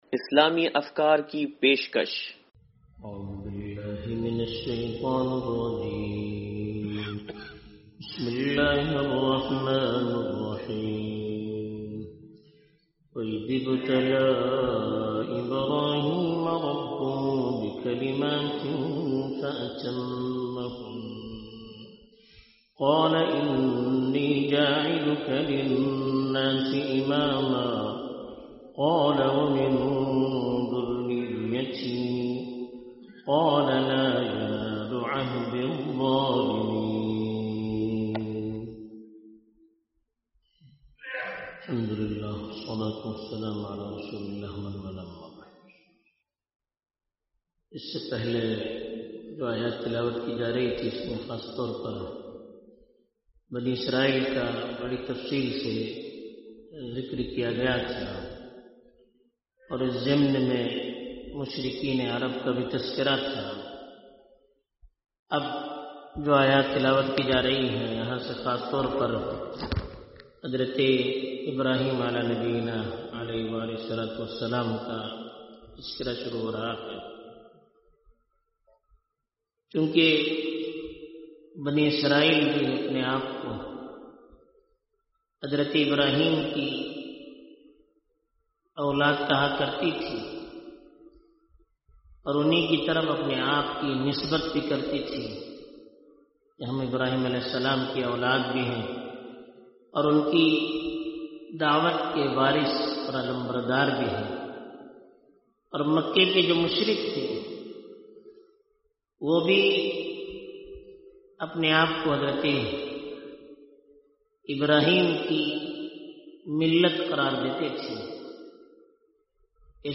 درس قرآن نمبر 0086
درس-قرآن-نمبر-0086-2.mp3